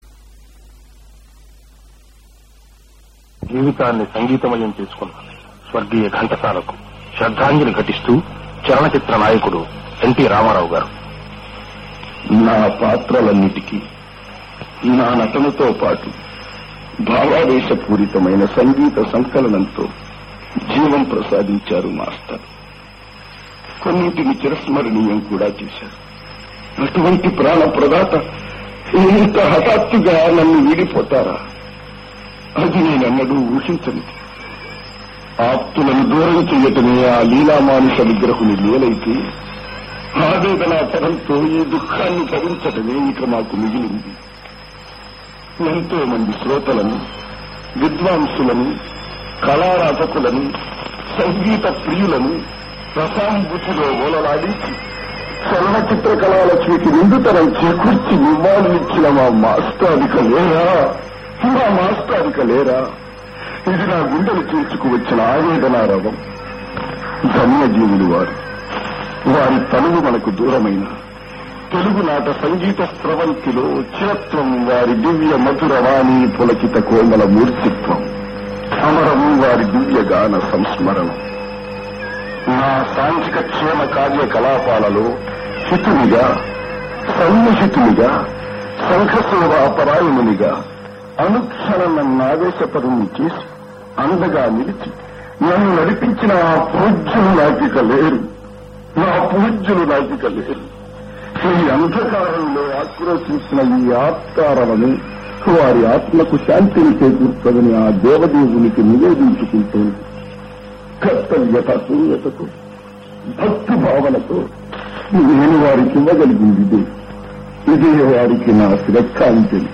ఘంటసాల గారి ఇంటర్వూ